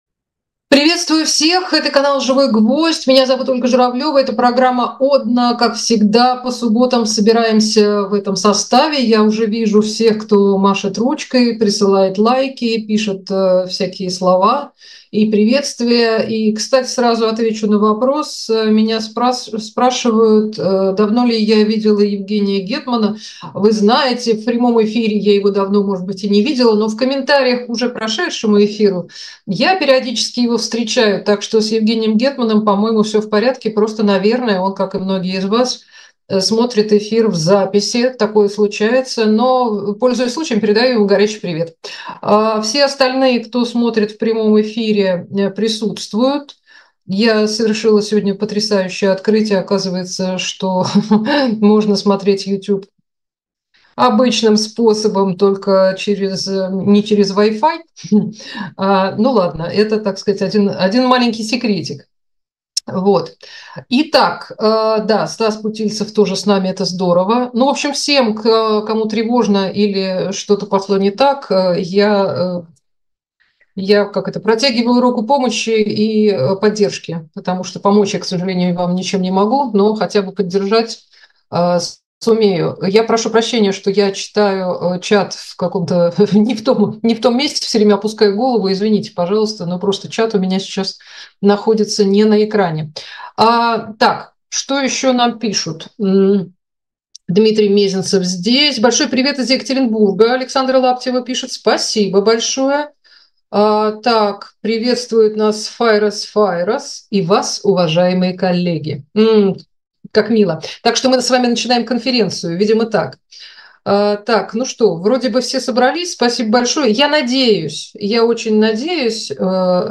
Ольга Журавлёва общается с вами в прямом эфире